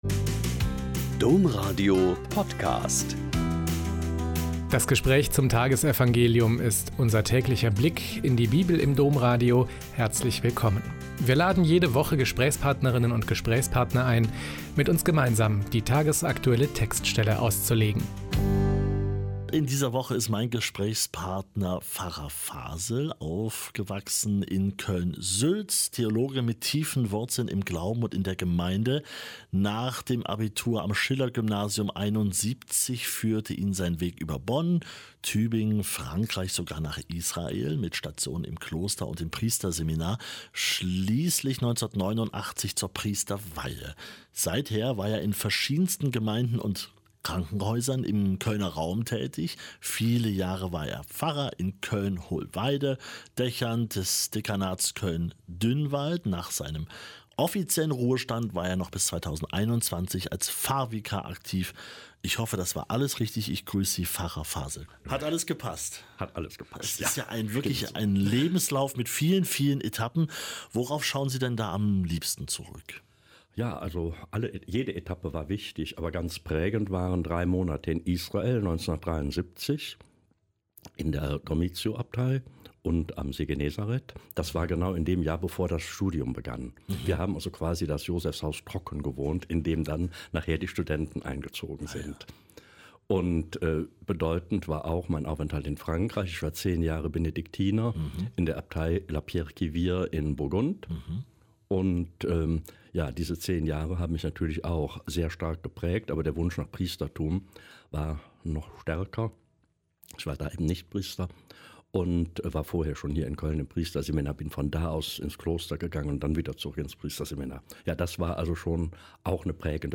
Lk 12,13-21 - Gespräch